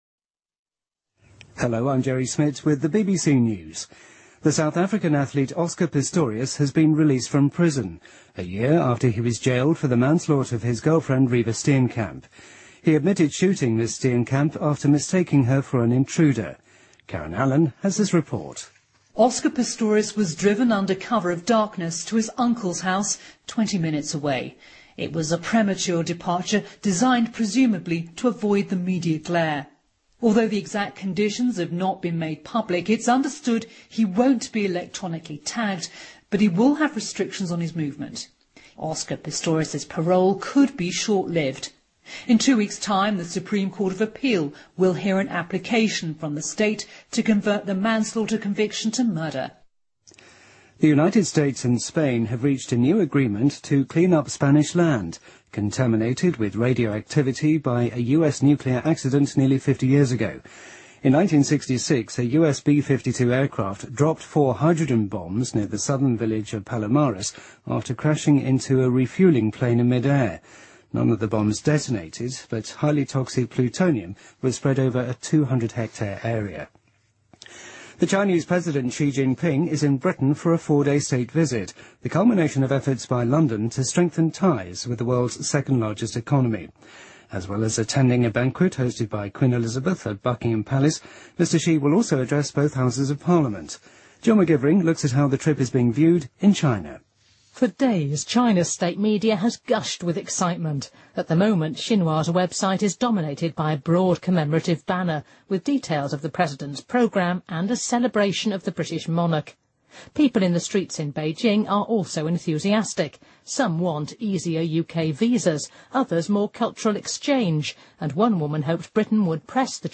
BBC news,2015-10-21新闻